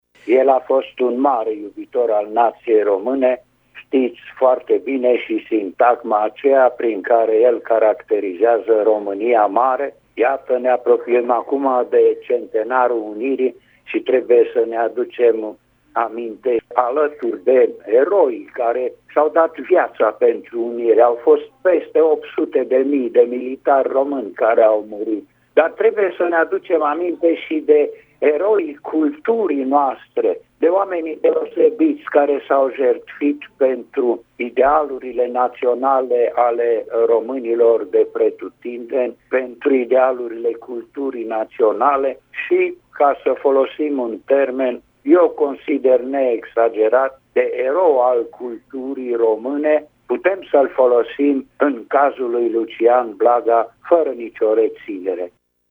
Radio România Tg. Mureș găzduiește vineri, 28 iulie, de la ora 12, o sesiune de comunicări științifice intitulată „Lucian Blaga și opera sa în secolul XXI”.
În contextul apropierii sărbătoririi centenarului Unirii, academicianul Alexandru Surdu a ținut să evidențieze și ideile esenţiale ale lui Blaga legate de filosofia culturii şi de specificul naţional românesc: